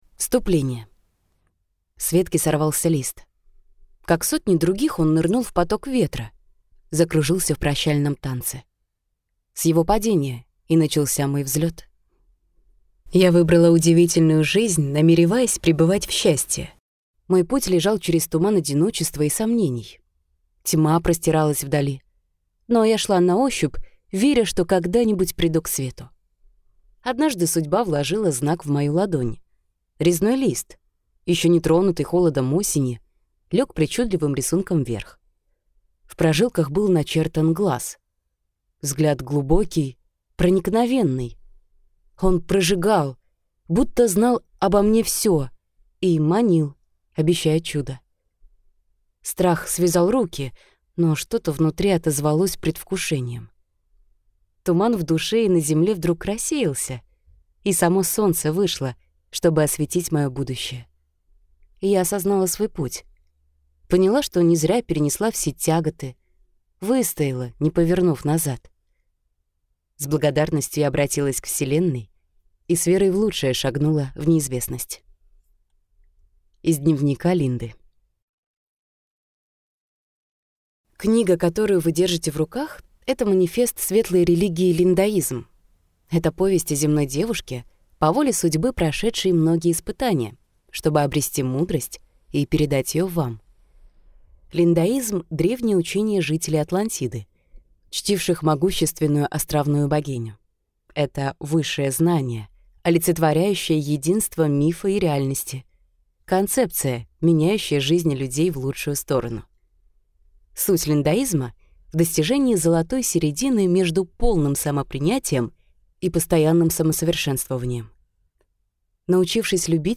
Аудиокнига Линдаизм. Вирус знаний | Библиотека аудиокниг